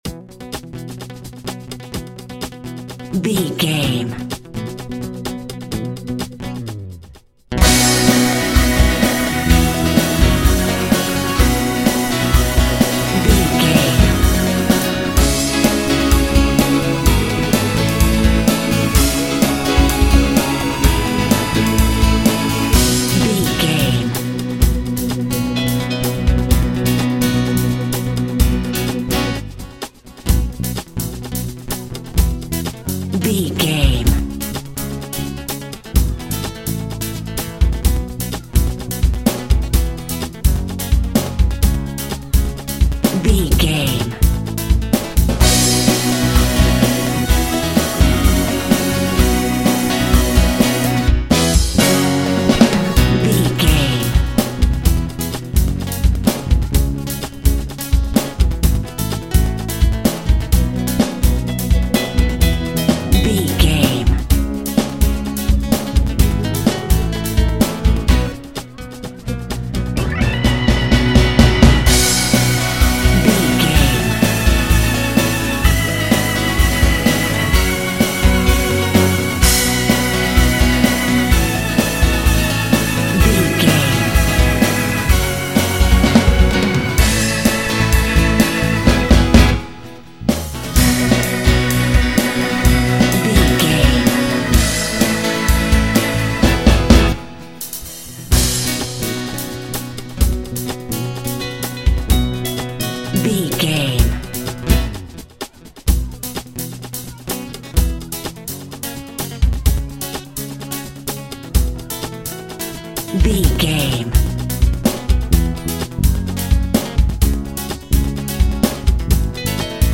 Anthem American Rock.
Epic / Action
Fast paced
Ionian/Major
heavy rock
distortion
hard rock
Instrumental rock
drums
bass guitar
electric guitar
piano
hammond organ